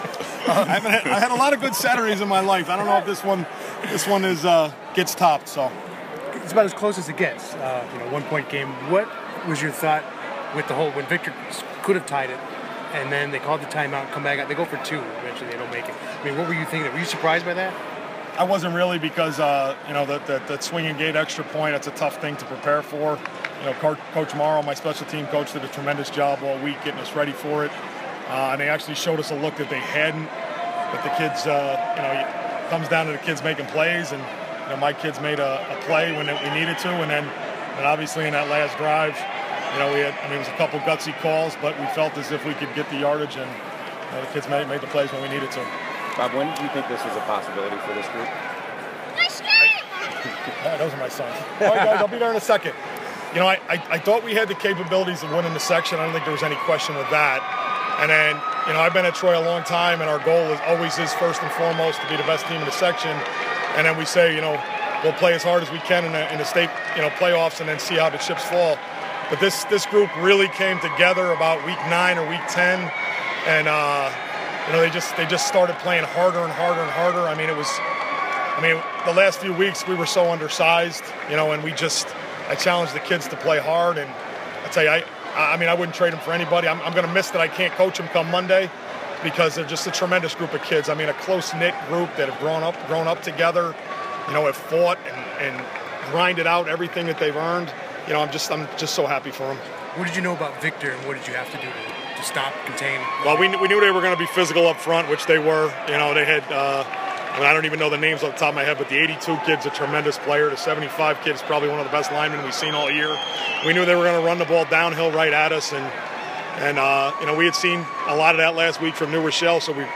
MP3 audio Some weekend interviews In Syracuse: